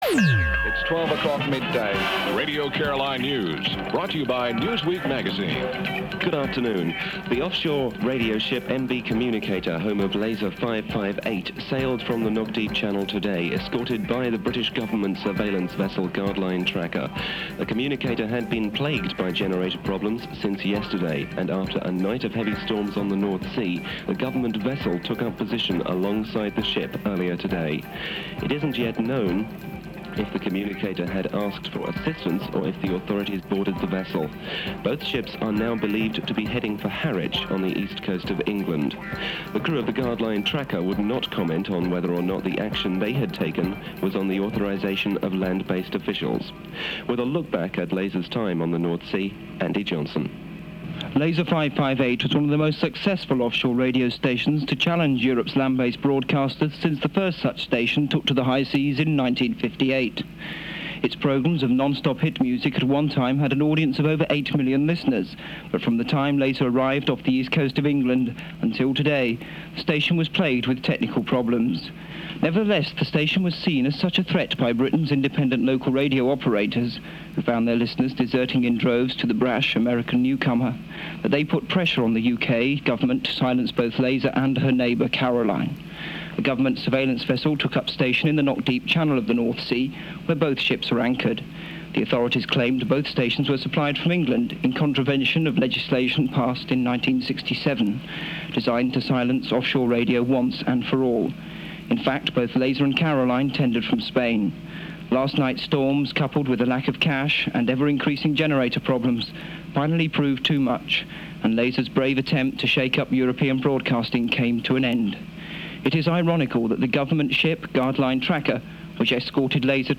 Radio Caroline news reporting the close of Laser 558
Caroline news reporting close of Laser 558.mp3